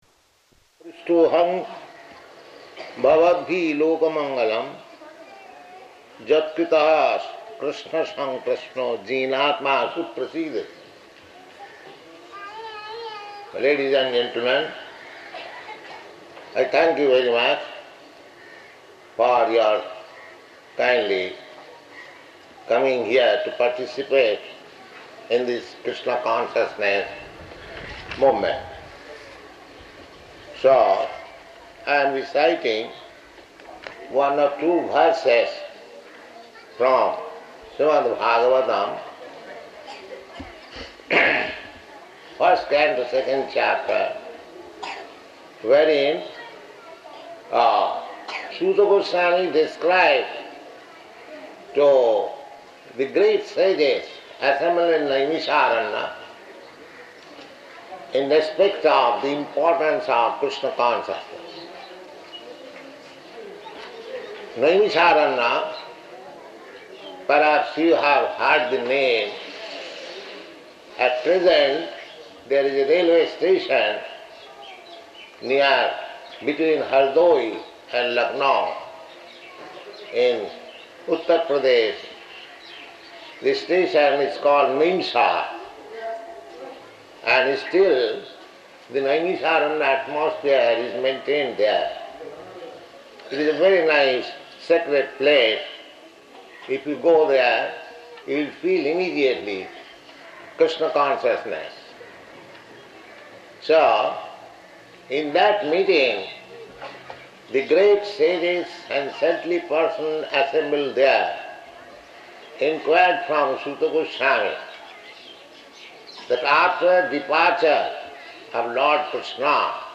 Location: Visakhapatnam